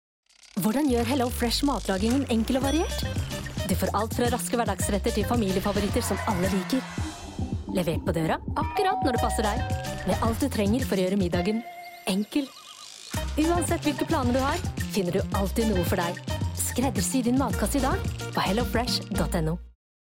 Female
Friendly, Confident, Character, Corporate, Energetic, Natural, Warm, Engaging
Microphone: SM Pro Audio MC01